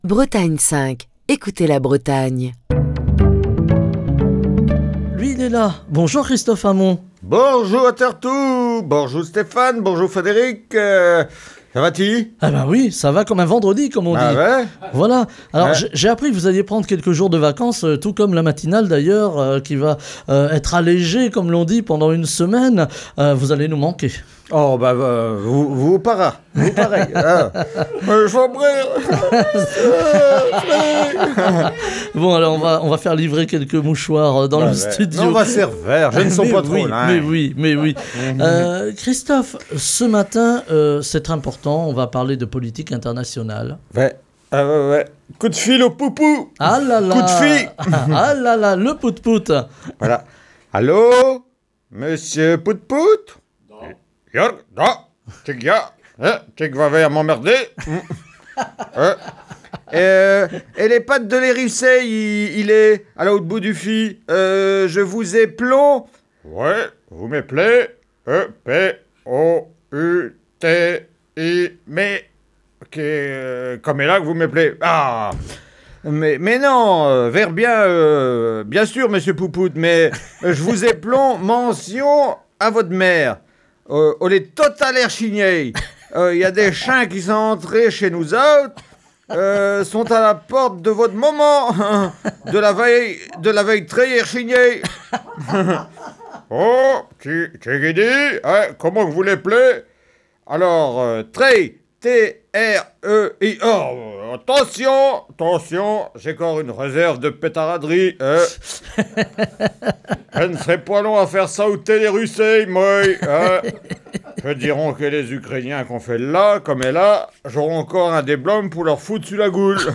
Chronique du 28 octobre 2022.